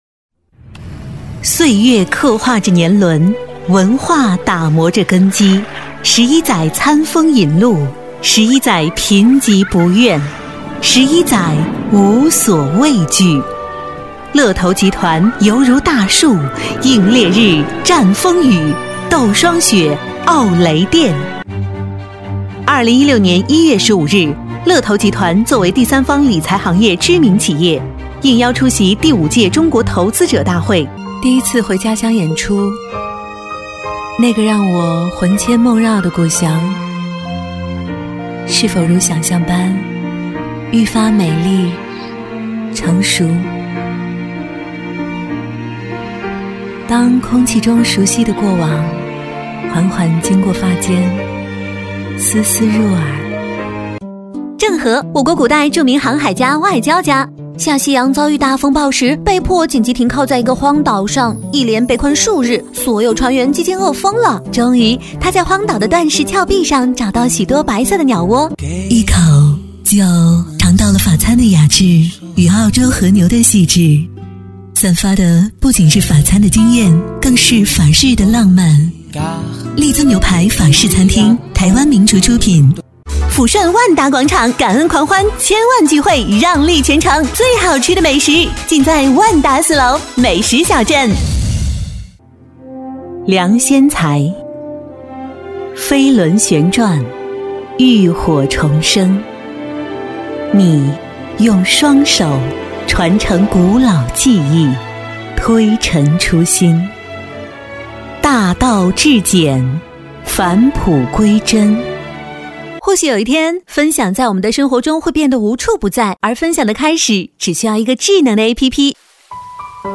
女S143 国语 女声 飞碟说MG动画 美图手机AnglaBABY猫咪篇 积极向上|时尚活力|亲切甜美|脱口秀